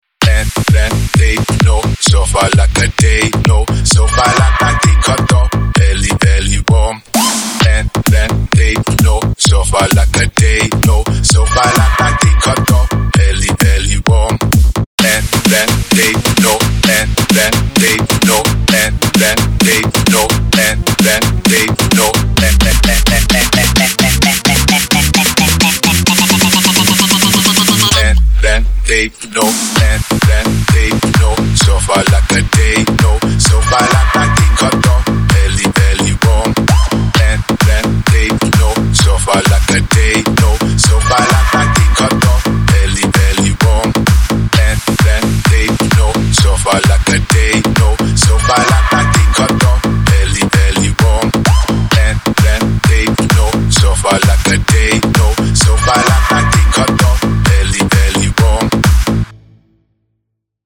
בנוי טוב מלודיה שחוזרת על עצמה ומשעממת